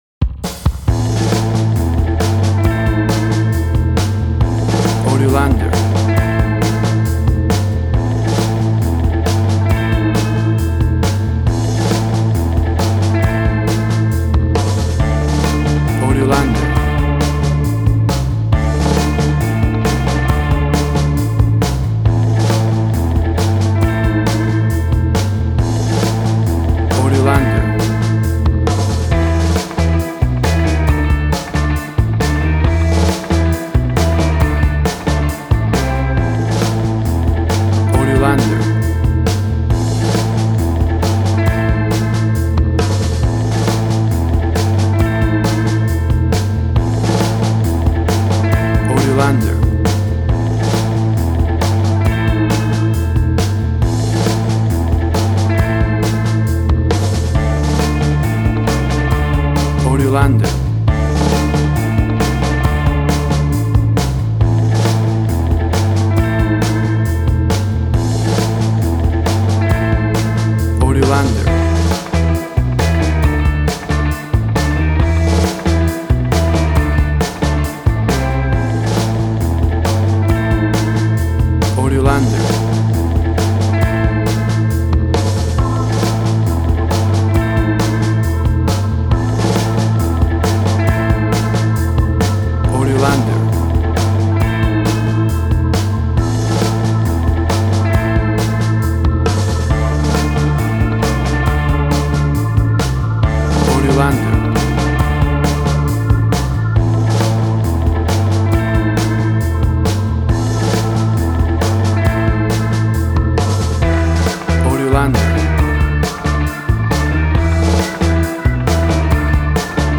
WAV Sample Rate: 16-Bit stereo, 44.1 kHz
Tempo (BPM): 68